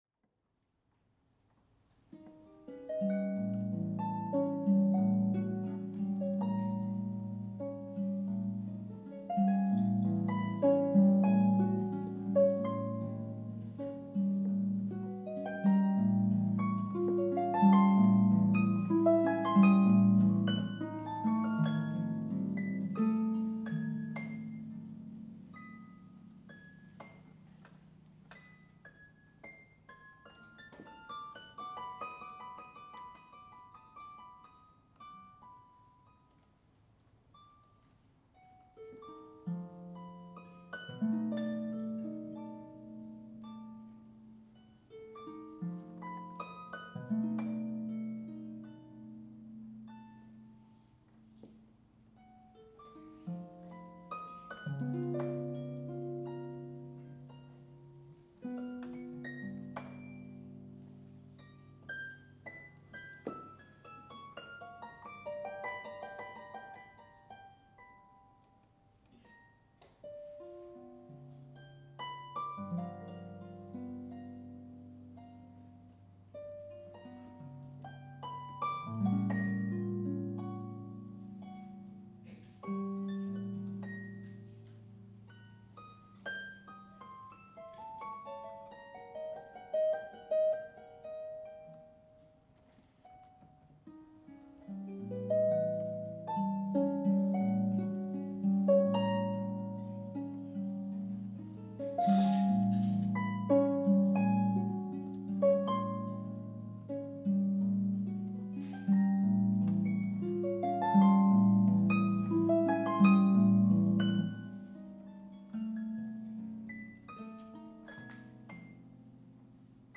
for two pedal harps